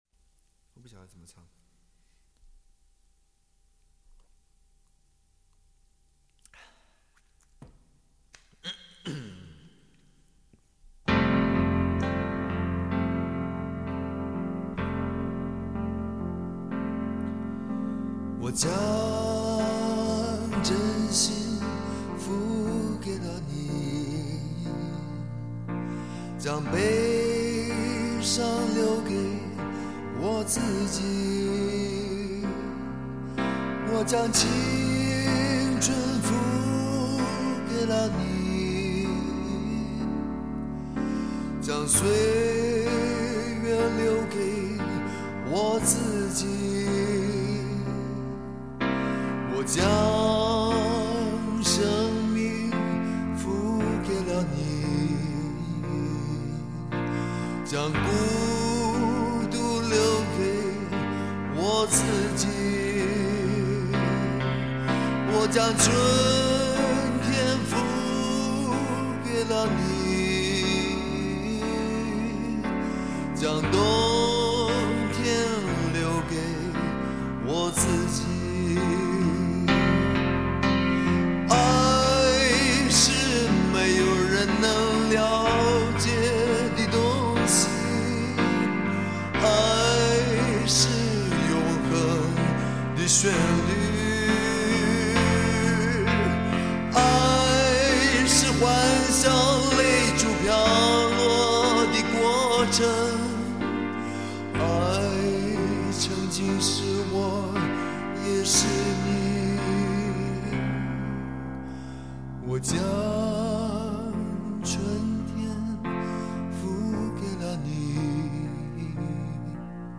一架钢琴自弹自唱营造出来的空间感